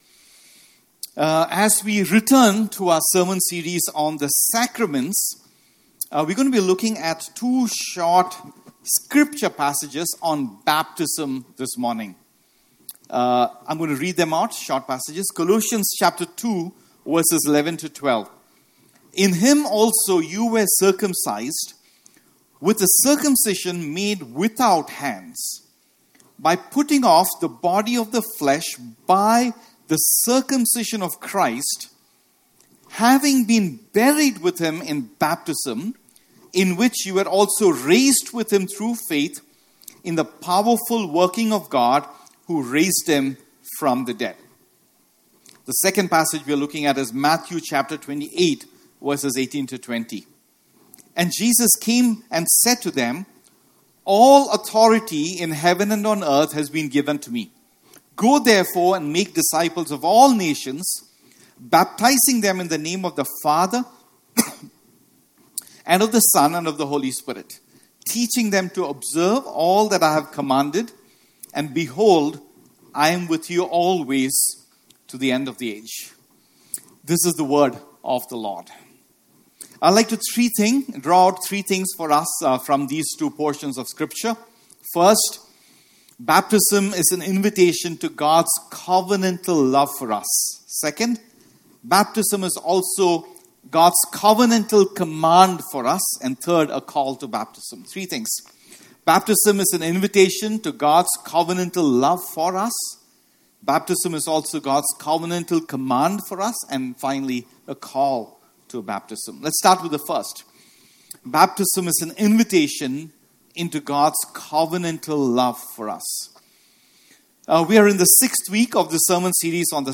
Audio-Part-6-Baptism-Sermon.mp3